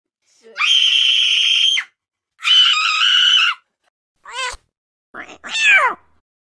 Cheezborger screaming, from Chikn Nuggit.
cheezborger-screaming.mp3